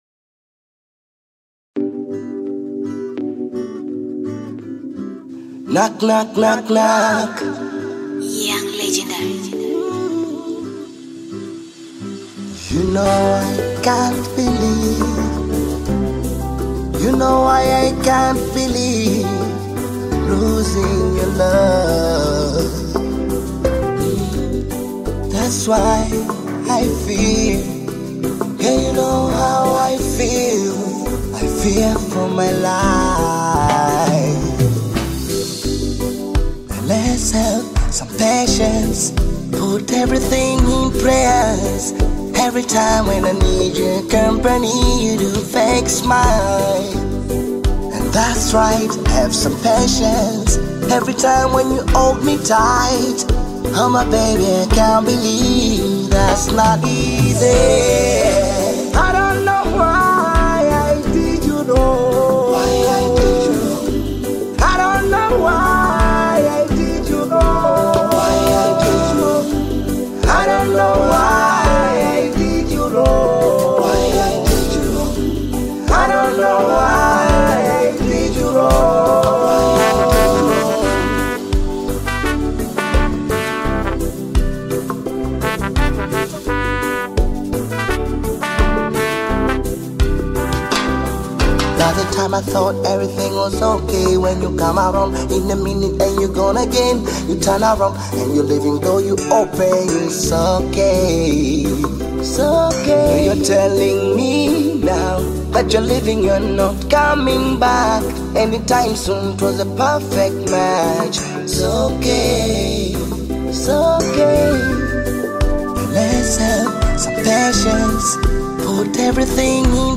AudioBongo Hip-HopTanzanian Music